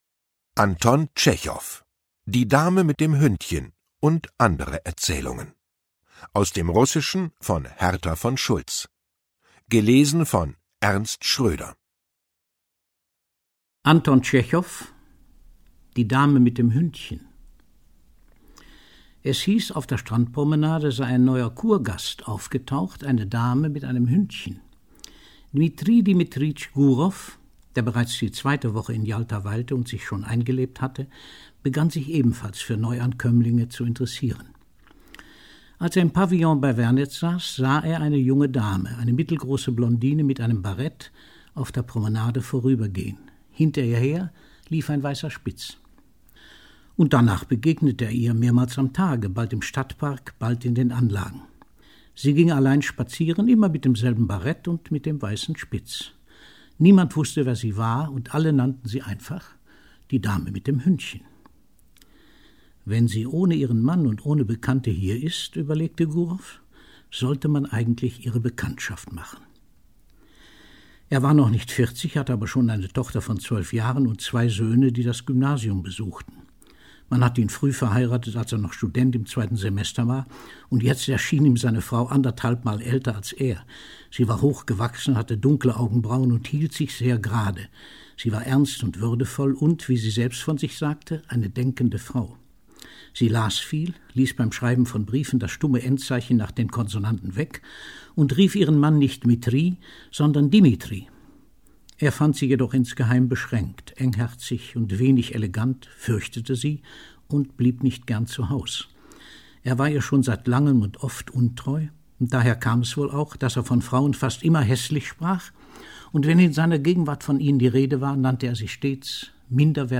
Ungekürzte Lesungen mit Ernst Schröder (1 mp3-CD)
Ernst Schröder (Sprecher)